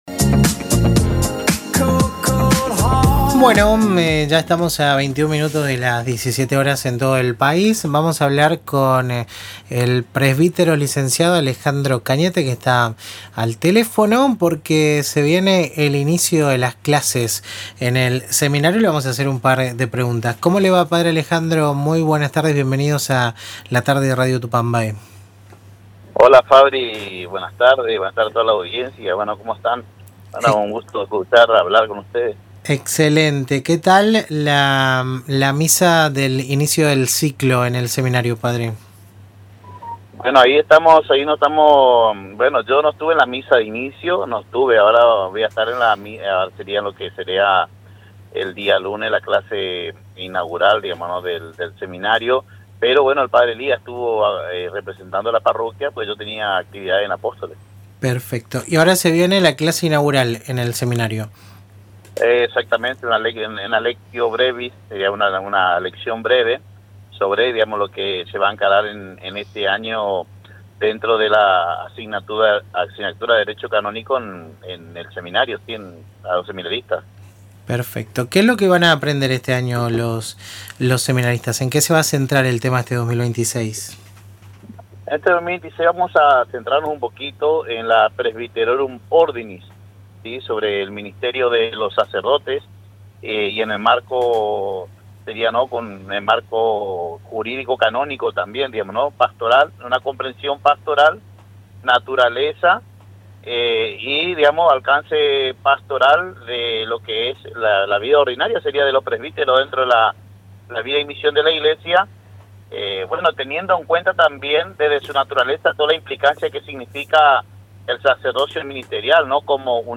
Aquí, el audio de la entrevista completa realizada en Radio Tupambaé: